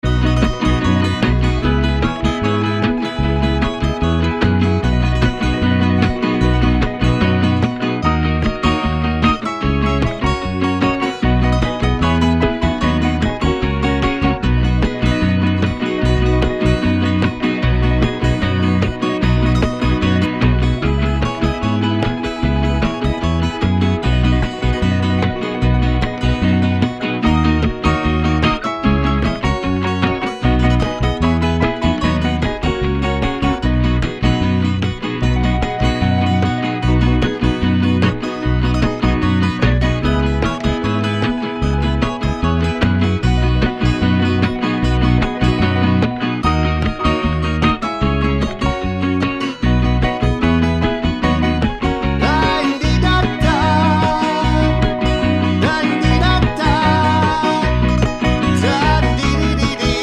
no harmonica with Backing Vocals Pop (1970s) 3:24 Buy £1.50